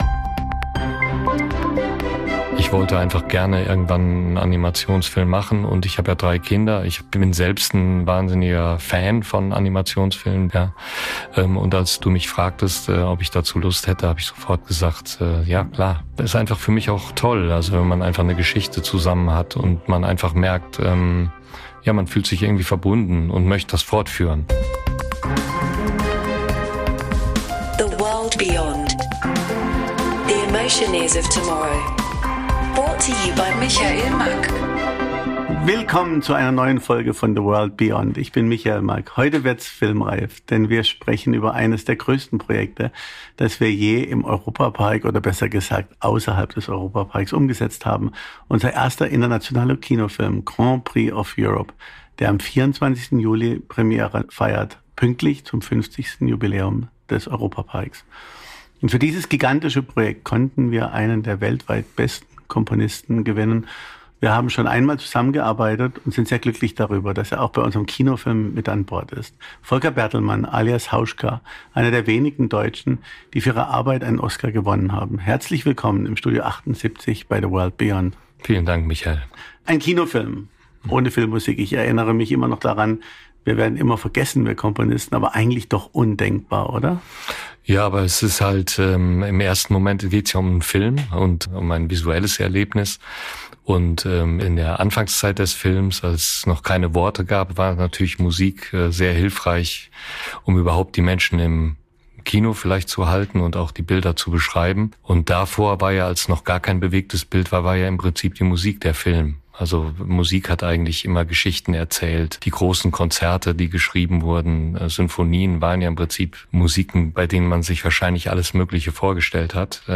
Find out by listening to fascinating conversations in this exclusive time-travel adventure!—MACK One is an international creator and consultant for innovative theme park design, media-based entertainment, and media content. We create and develop ideas for the attractions industry of tomorrow.—This podcast is a MACK One production, recorded at Studio78 in Europa-Park.